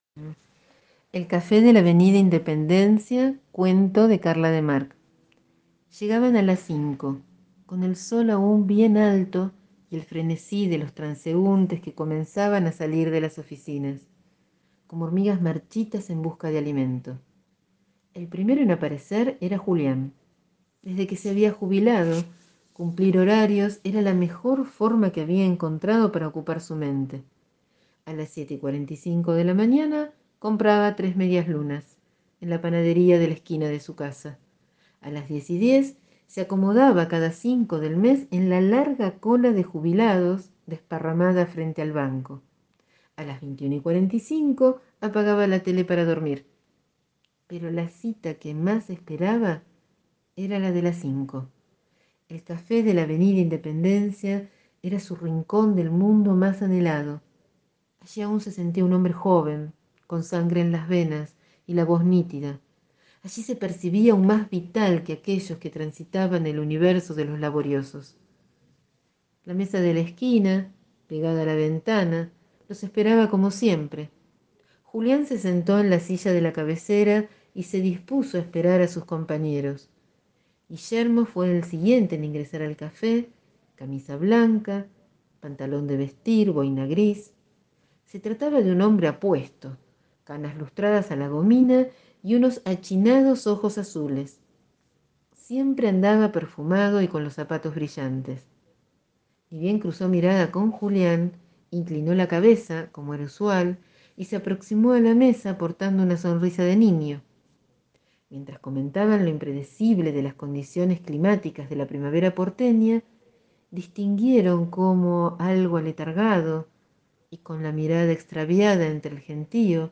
Les leo , entonces un cuento, en el que queda dicho que el deseo y la apuesta pueden sostenerse hasta el final.